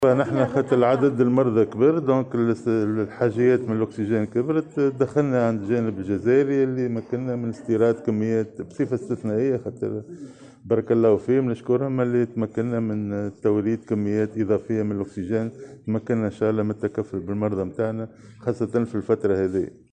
وأضاف اليوم خلال نقطة إعلامية نظمتها الوزارة، أنه تم الاتفاق مع الجزائر على توريد كميات إضافية من الأكسيجين وذلك بصفة استثنائية.